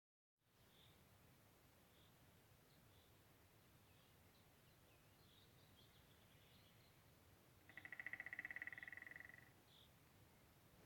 White-backed Woodpecker, Dendrocopos leucotos
StatusPermanent territory presumed through registration of territorial behaviour (song, etc.)
Notes/hiperaktīvs